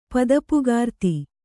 ♪ padapugārti